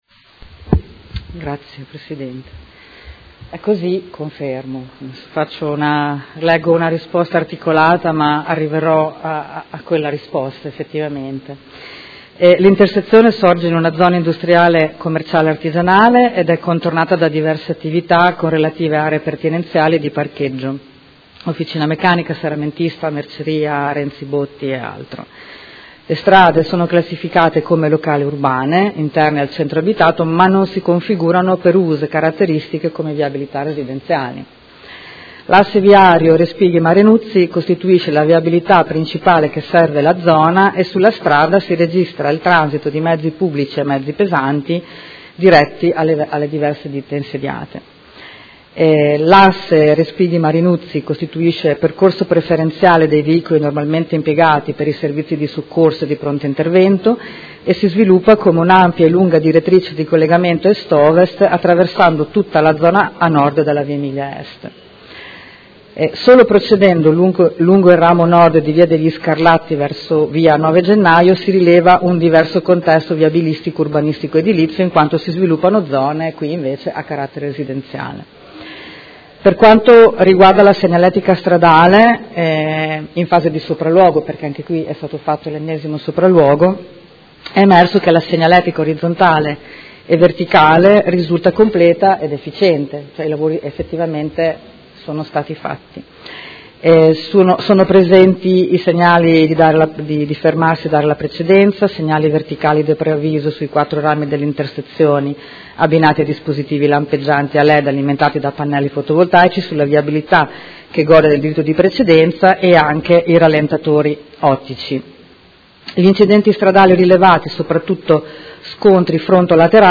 Seduta dell’8/11/2018. Risponde a interrogazione del Consigliere Carpentieri (PD) avente per oggetto: Viabilità su Via Respighi – Via Marinuzzi incrocio con Via Degli Scarlatti